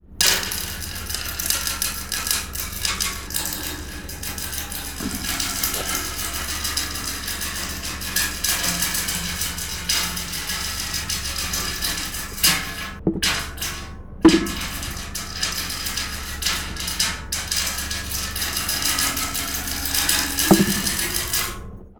Un poco de arte sonoro con farolas caidas y abandonadas en descampados. Las farolas estan vacias por dentro y podemos jugar con su percusión.
[ENG] The sound with lampposts on the floor. The streetlights are empty and I can play with his percussion.
farola.wav